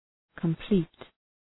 Προφορά
{kəm’pli:t}
complete.mp3